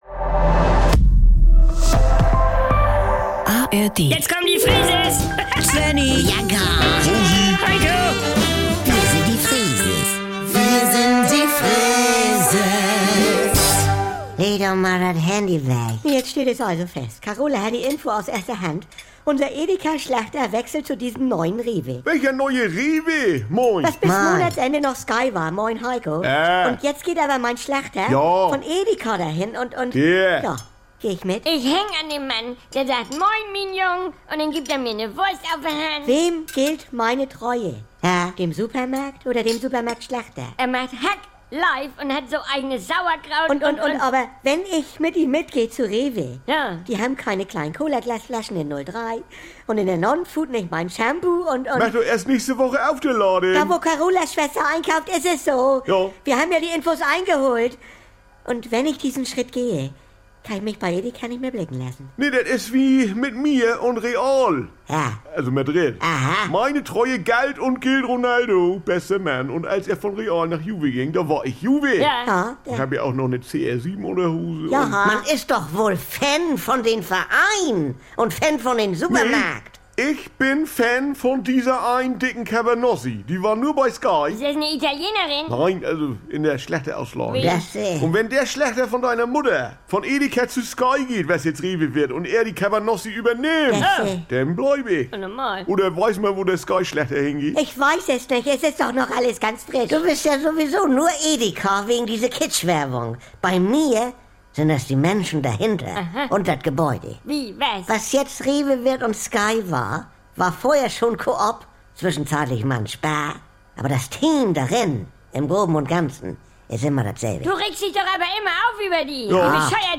NDR 2 Saubere Komödien Unterhaltung Komödie NDR Freeses Comedy